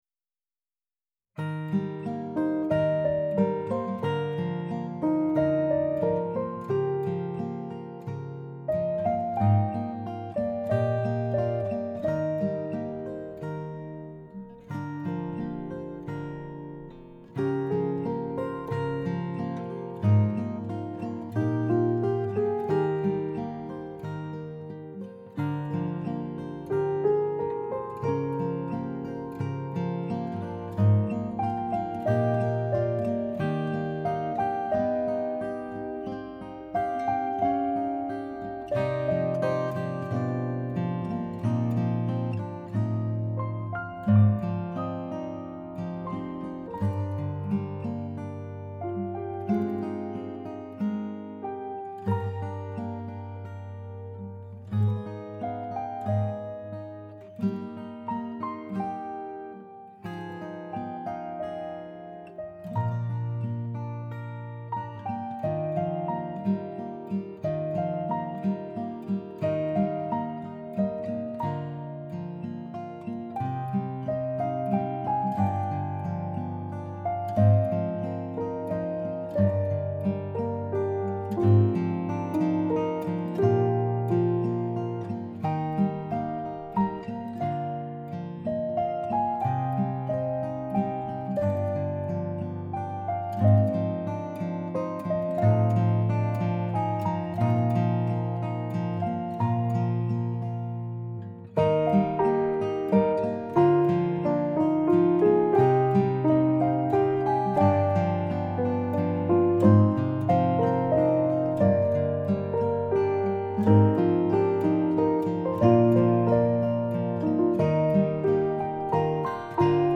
You Were There Guitar & Piano 9-9-18
you-were-there-piano-gtr-9-9-18.mp3